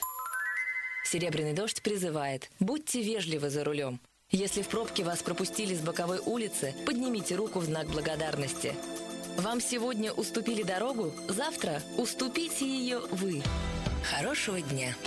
Проморолик "Будьте вежливы за рулём" (Серебряный дождь, 2006)